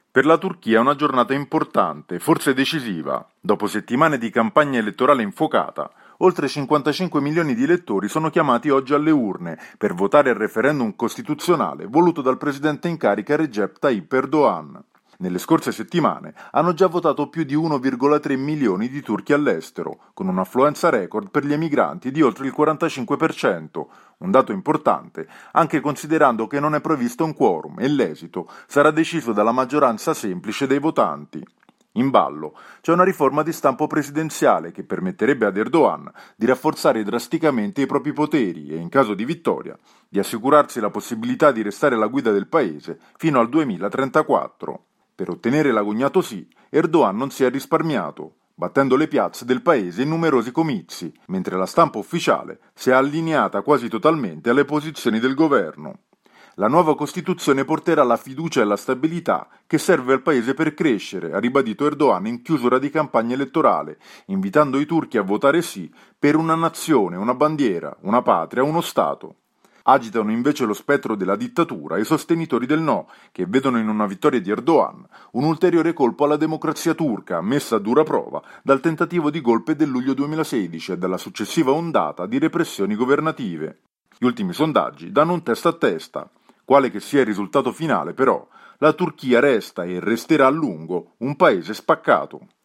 per il GR di Radio Capodistria